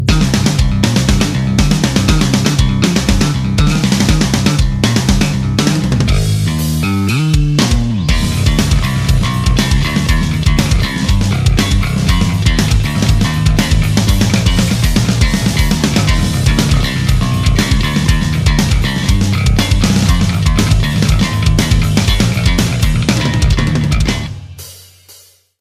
• Качество: 320, Stereo
без слов
инструментальные
Industrial
бас-гитара